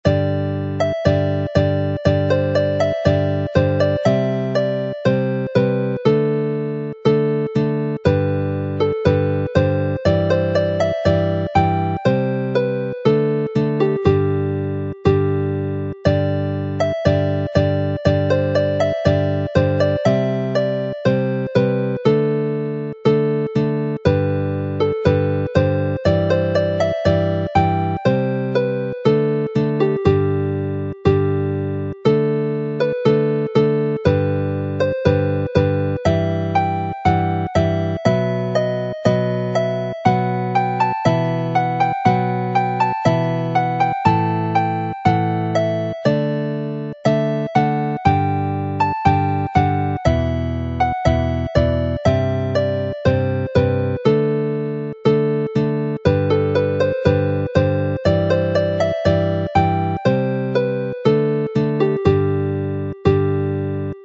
Here it is introduced in what might well have been its original form, Elizabethan in style before it transforms to the key and speed normally used for the dance.